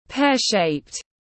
Hình quả lê tiếng anh gọi là pear-shaped, phiên âm tiếng anh đọc là /ˈpeə.ʃeɪpt/.
Pear-shaped /ˈpeə.ʃeɪpt/